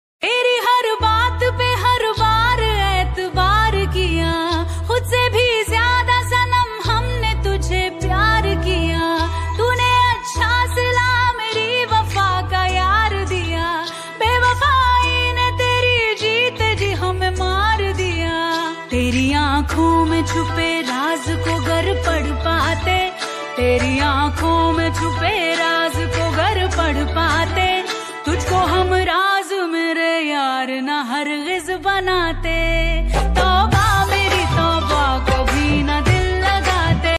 Sad Song Ringtone